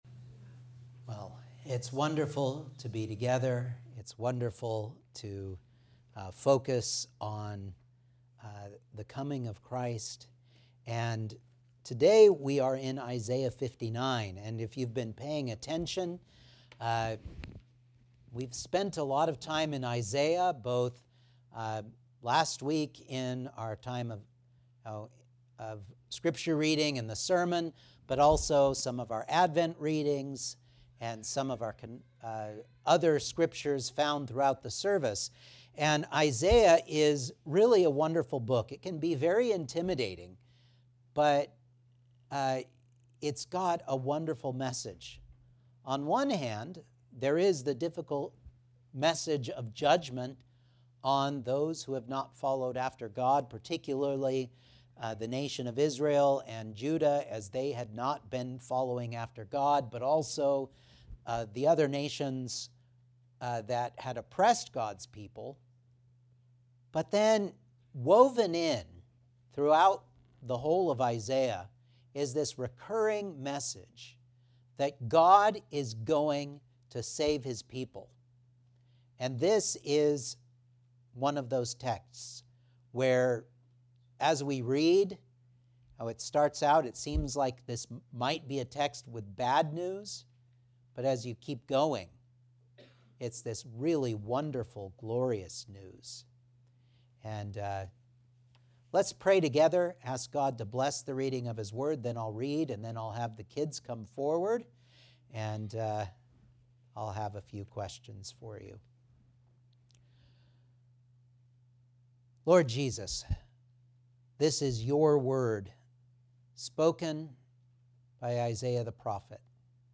Isaiah 59 Service Type: Sunday Morning Outline